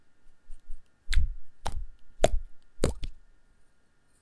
• slime blop toy.wav
A slipe popping out of its plastic can, recorded with a Tascam DR40 in a studio.
slime_blop_toy_s3e.wav